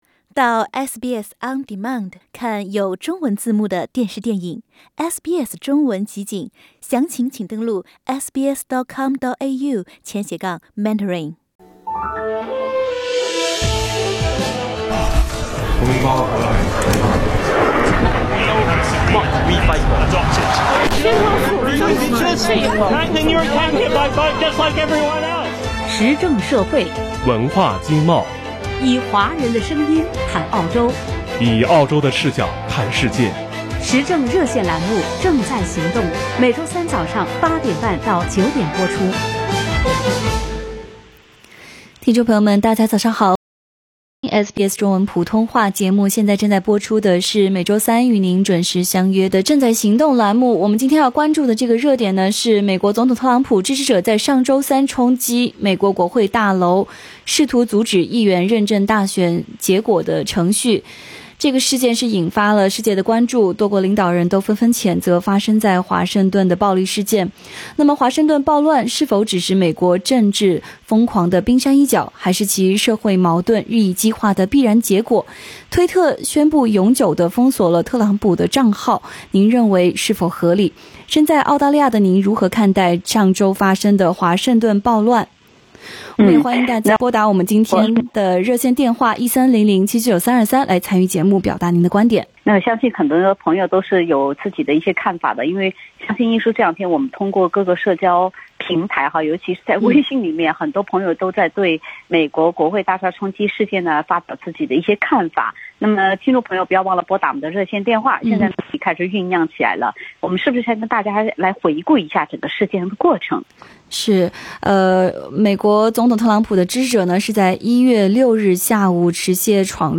热线节目中在澳大利亚的华人积极发表了他们对这一问题的看法。